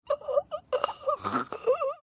Phasmophobia death Sound Button
Phasmophobia death sound button is a short, punchy audio clip that people love using in memes, gaming streams, and reaction edits.